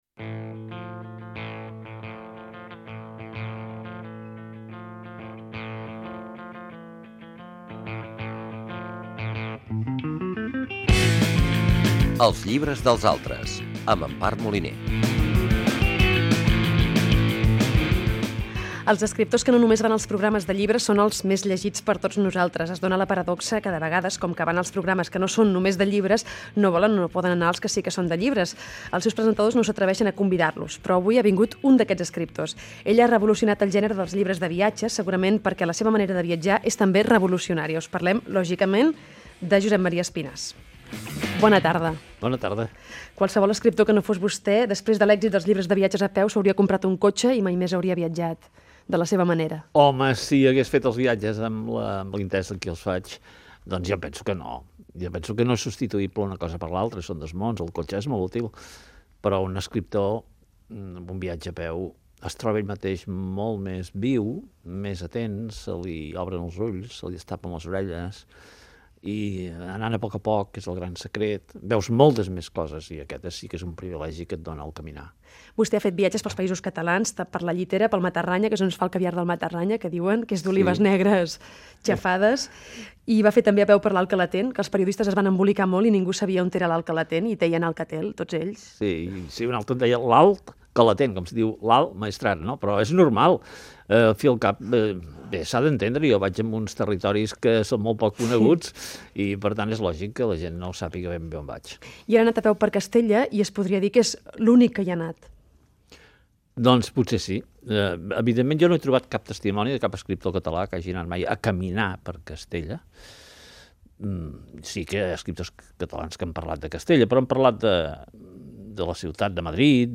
FM
Fragment extret de l'arxiu sonor de COM Ràdio.